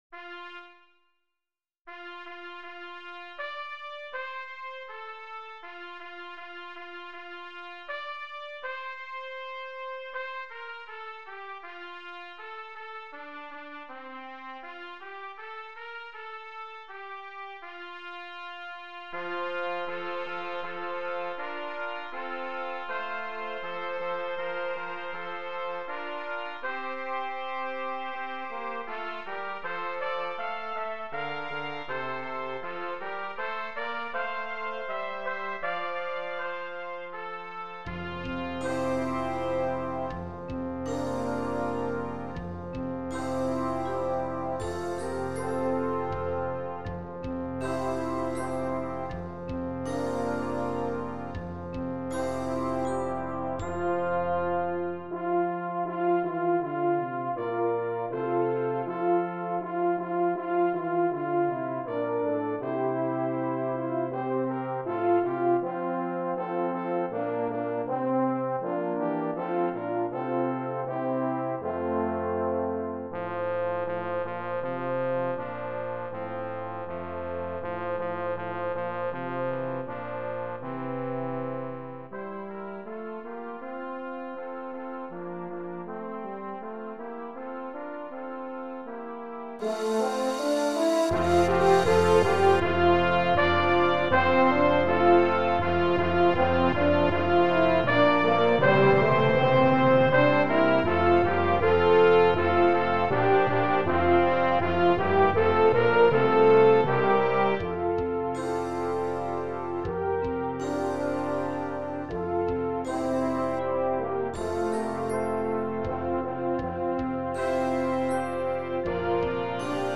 Voicing: 10 Brass